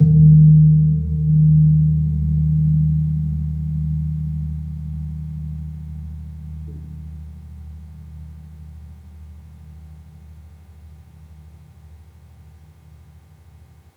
Gong-D#1-p.wav